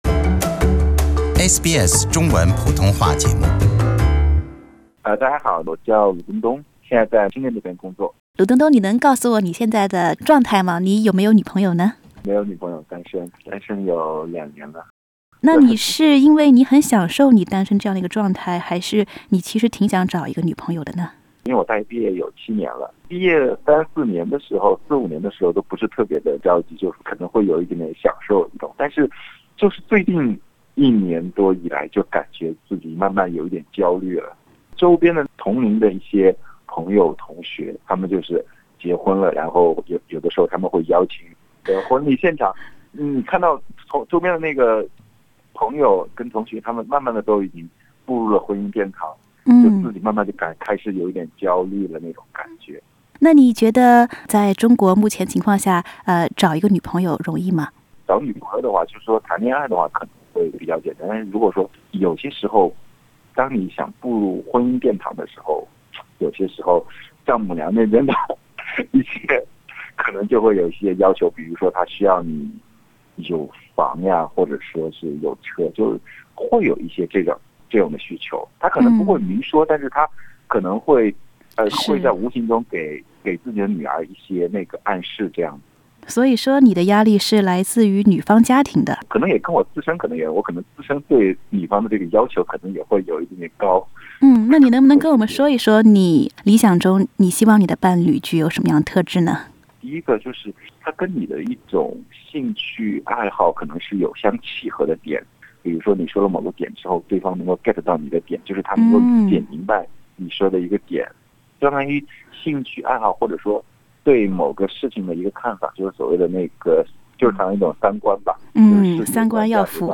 我们采访了两位在中国工作生活，单身时间已超过两年的男士，来听听他们的单身故事。